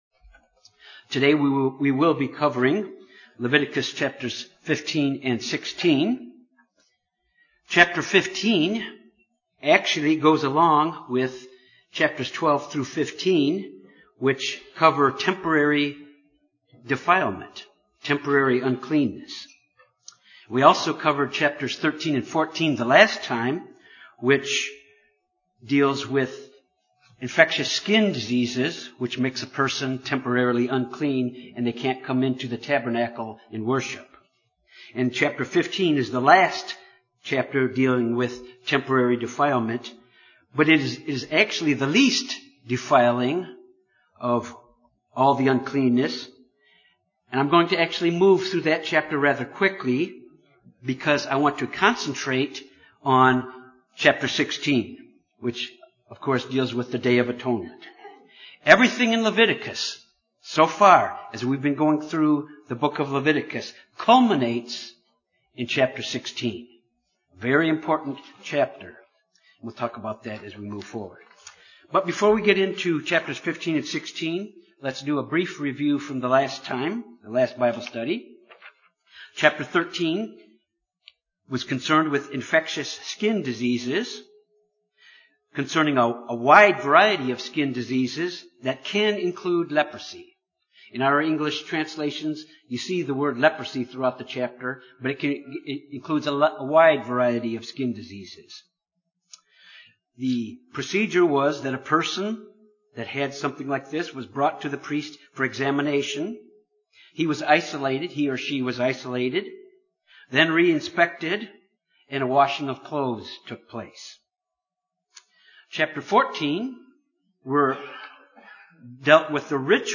This Bible study examines the instructions for purification following bodily secretions; the kind that would render a person ritually unclean. Additionally, the purification instructions of the tabernacle and instructions concerning the Day of Atonement is also covered.